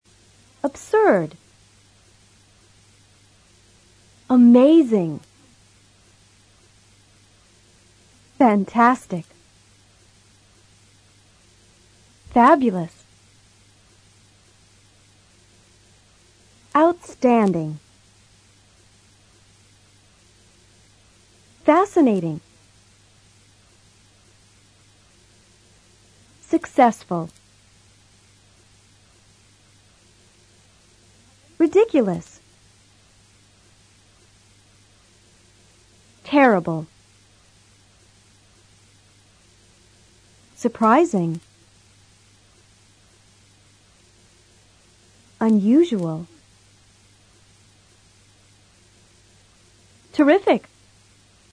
Escucha, en los próximos dos audios, la acentuación de estos adjetivos.